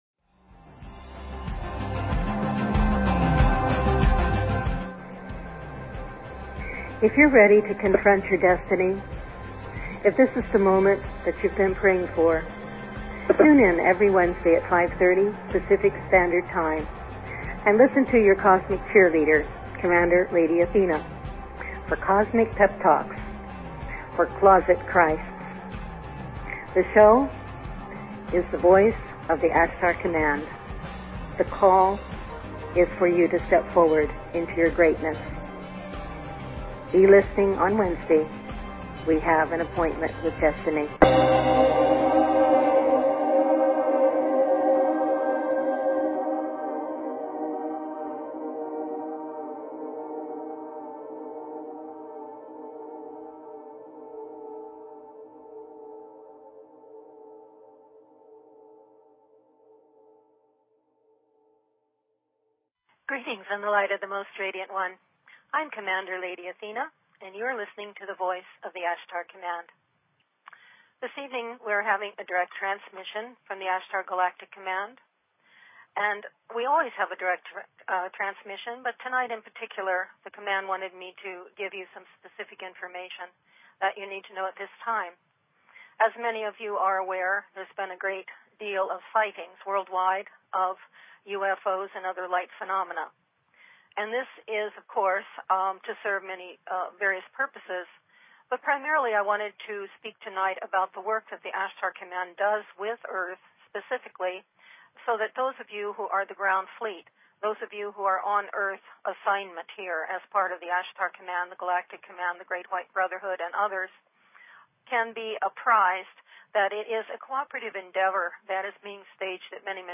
Talk Show Episode, Audio Podcast, The_Voice_of_the_Ashtar_Command and Courtesy of BBS Radio on , show guests , about , categorized as
Show Headline The_Voice_of_the_Ashtar_Command Show Sub Headline Courtesy of BBS Radio VIEW FULL DESCRIPTION (Note: Due to the internet provider suddenly disconnecting, the first part of the show has a brief interruption, so just wait and the rest continues after a couple seconds.)